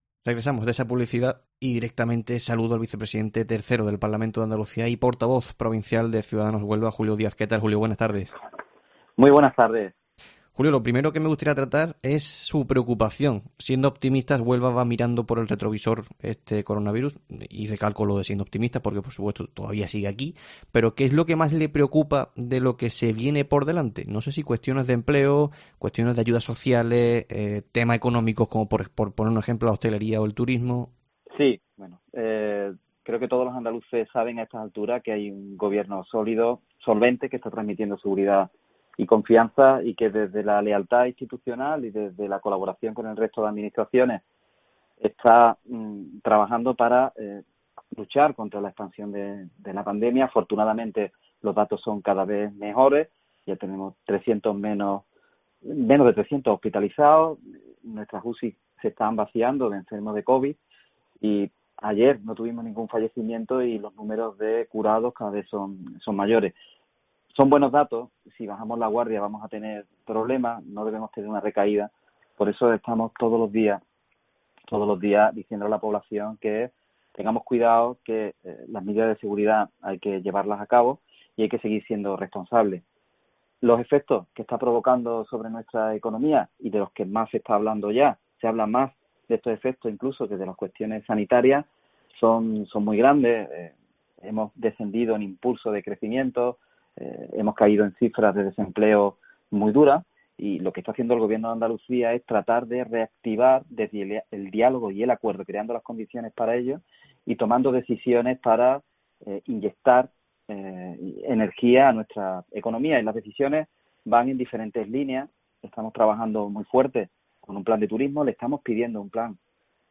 Abordamos y conocemos la postura de Ciudadanos en la crisis del coronavirus con el vicepresidente tercero del Parlamento de Andalucía y portavoz del partido en Huelva, Julio Díaz.